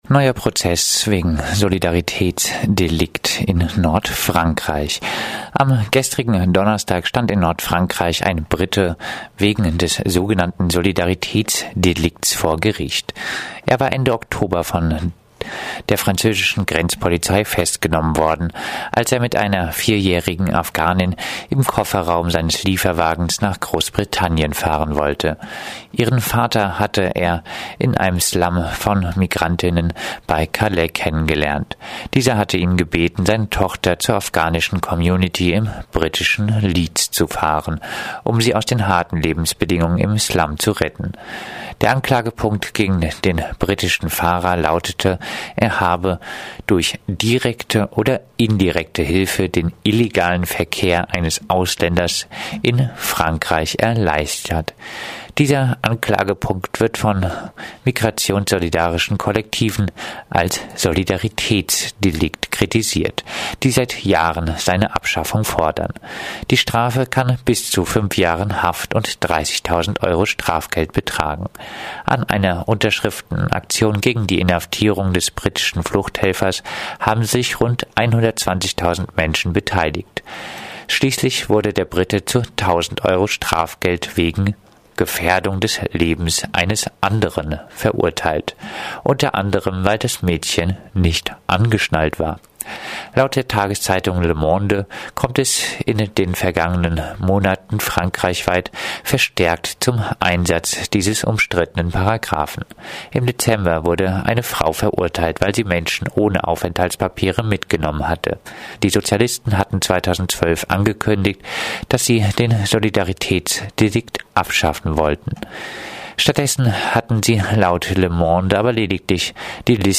Focus Europa Nachrichten am Freitag, 15. Januar 2016 um 9:30